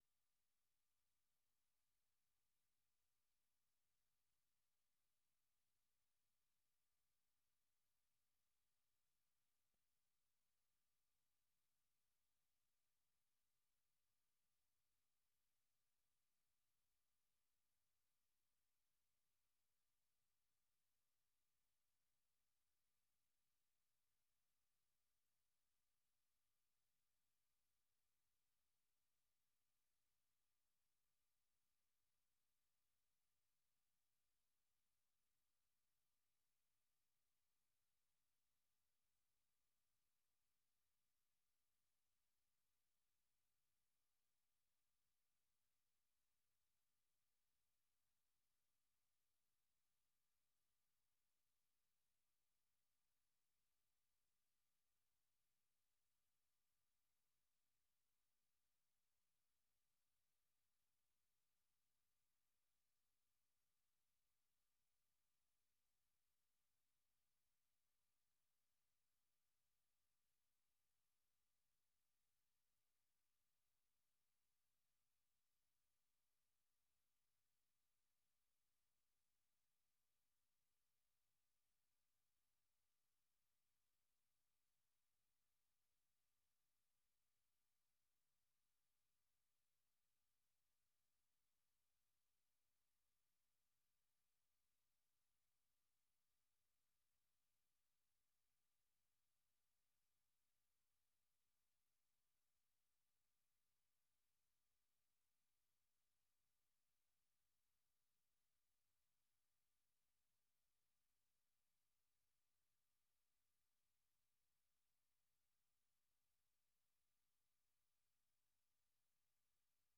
Learning English programs use a limited vocabulary and short sentences. They are read at a slower pace than VOA's other English broadcasts.